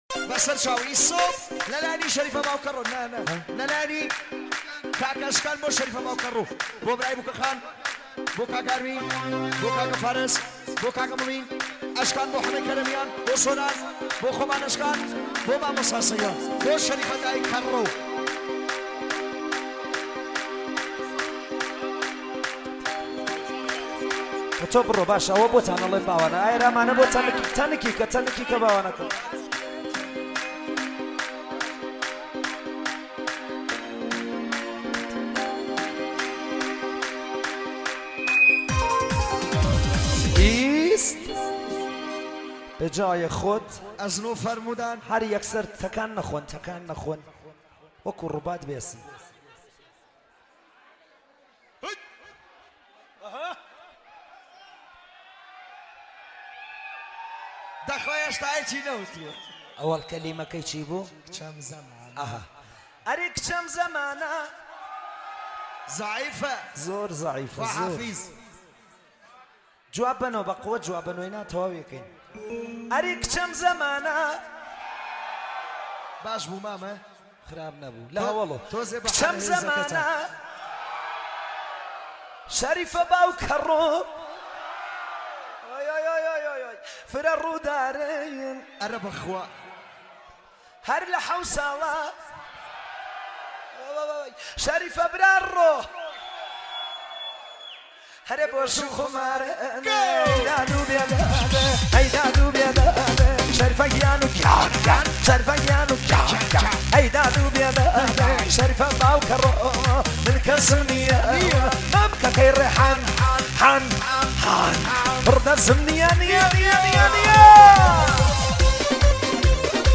آهنگ کردی شاد